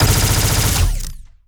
GUNAuto_Plasmid Machinegun Burst_07.wav